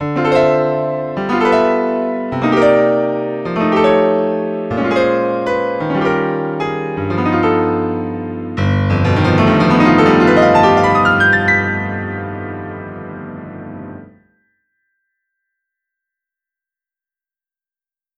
The obvious difference is that the level (volume) in the Windows version is much higher which I suspect makes it closer to digital clipping. Not excessively so to be highly distorted, but close enough to make the sound brighter.
And the difference in volume and brightness is obvious.